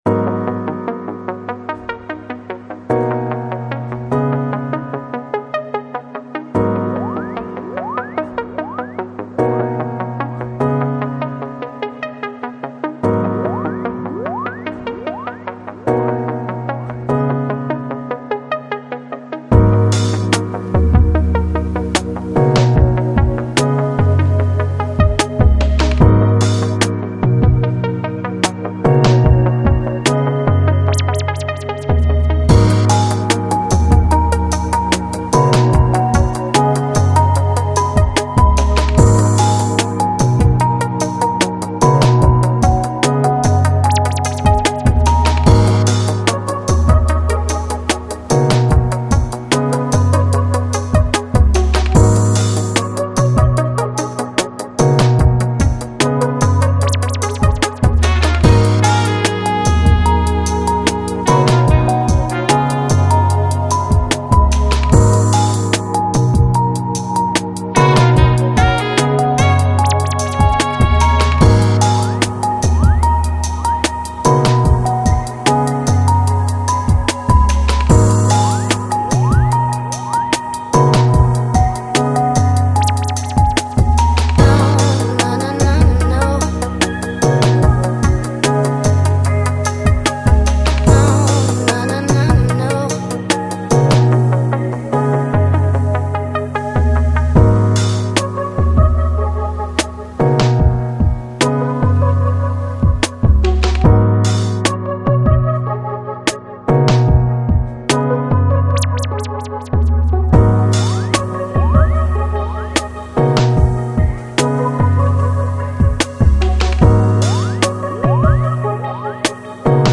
Жанр: Chill Out, Lounge, Balearic, Ambient